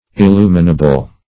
Illuminable \Il*lu"mi*na*ble\, a. Capable of being illuminated.
illuminable.mp3